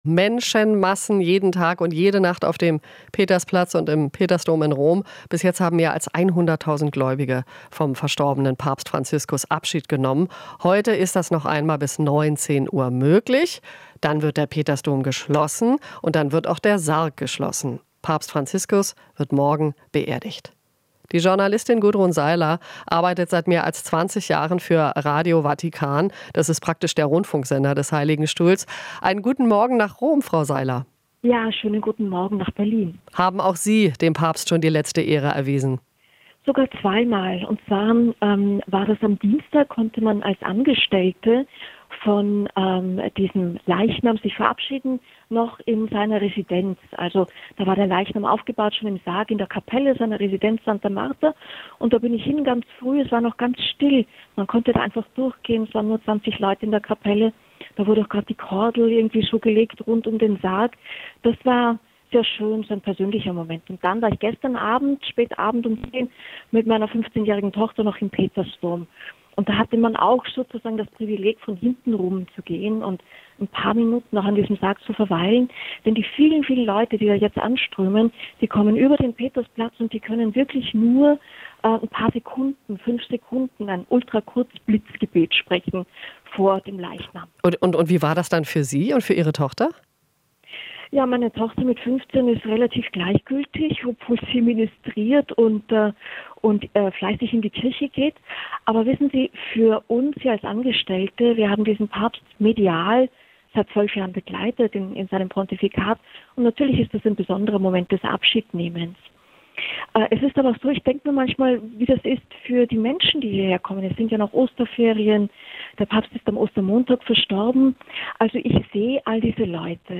Interview - Vatikanjournalistin: Für Franziskus kommt nach dem Tod die Party